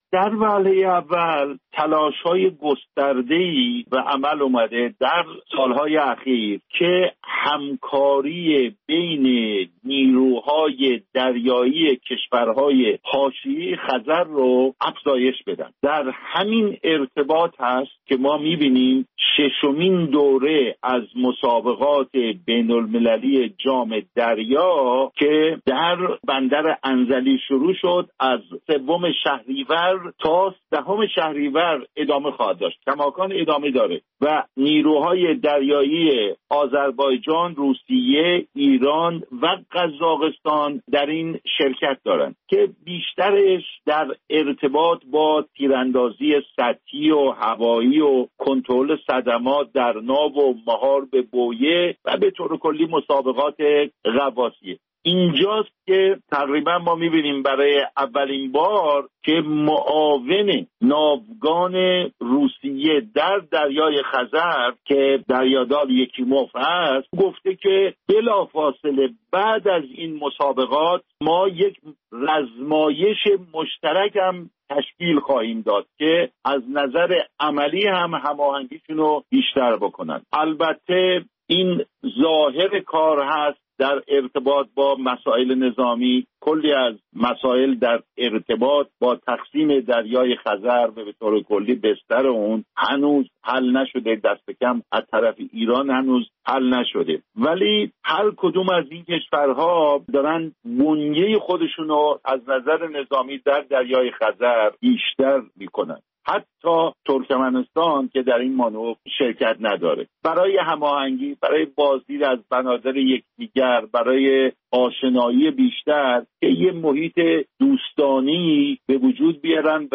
گفت‌و گویی